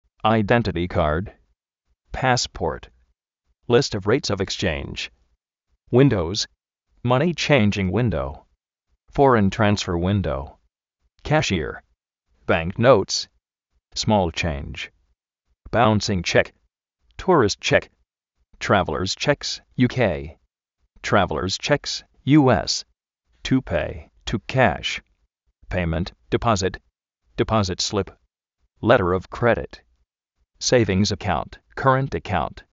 aidéntiti kard
pásport
list ov réits ov ikschéinsh